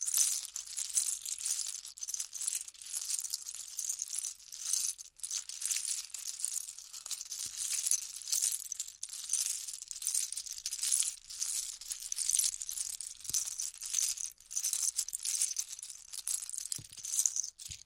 Звуки лего
Ковыряем пальцами в деталях лего